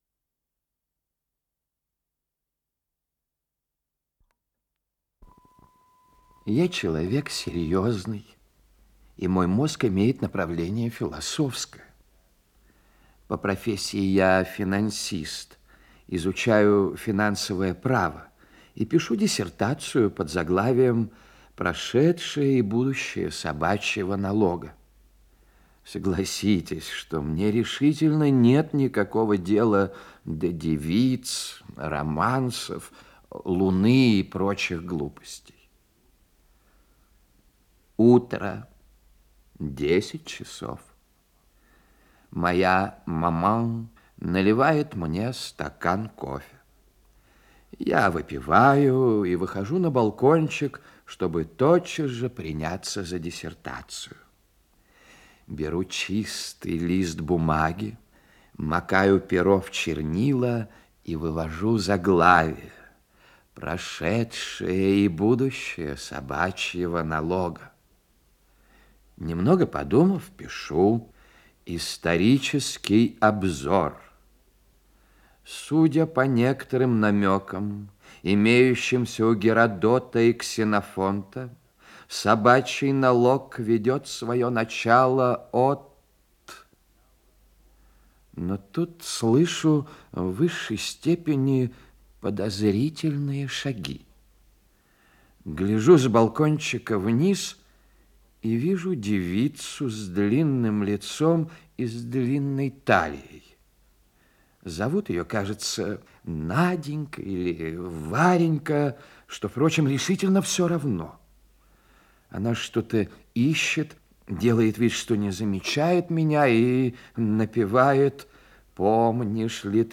Исполнитель: Геннадий Бортников - чтение
Рассказ